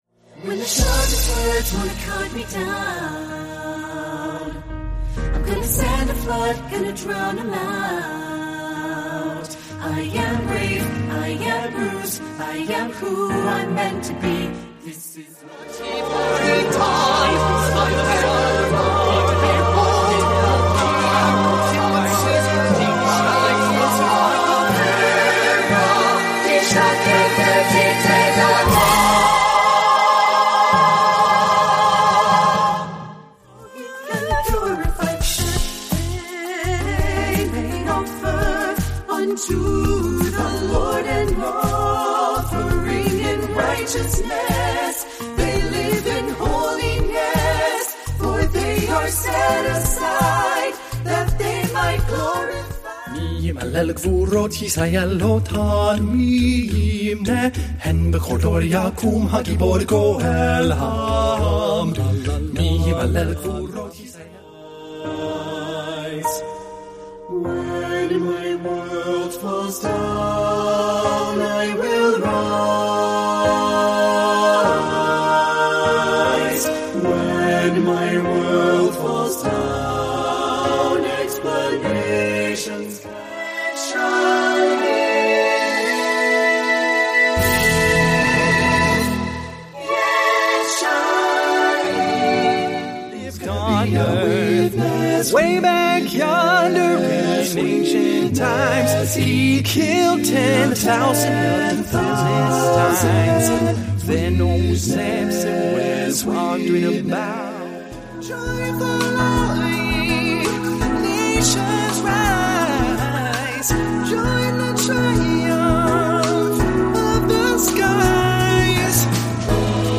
Choral Rehearsal Track Sample
Full Mix: All parts sounding at the same time.